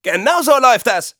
Der Scout schlägt auf seinen Hintern als würde er ein Pferd reiten und ruft: